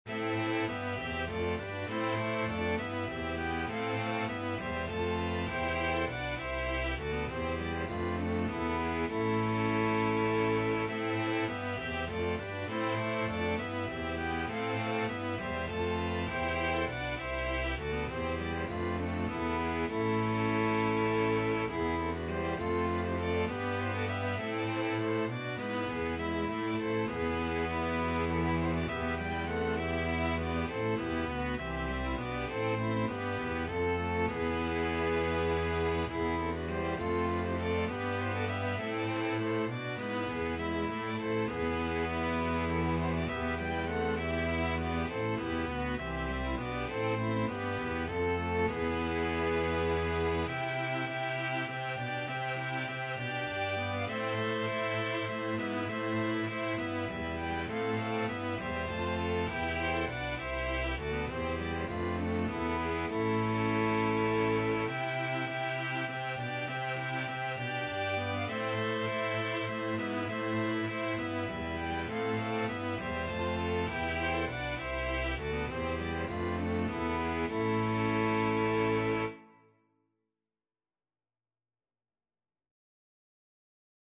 Free Sheet music for String Ensemble
Violin 1Violin 2ViolaCelloDouble Bass
G major (Sounding Pitch) (View more G major Music for String Ensemble )
3/2 (View more 3/2 Music)
Classical (View more Classical String Ensemble Music)
holborne_muy_linda_STRE.mp3